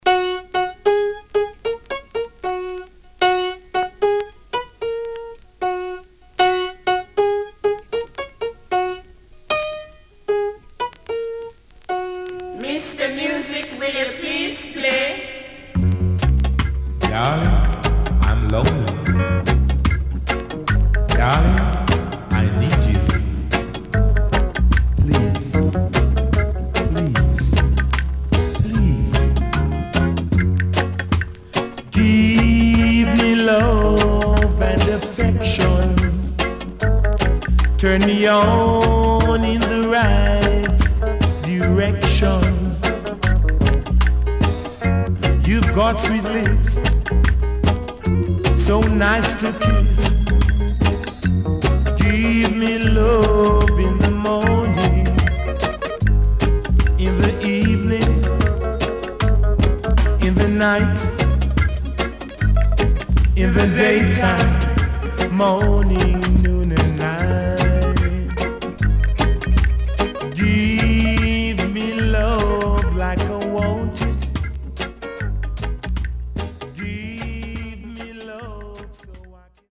SIDE A:盤質は良好です。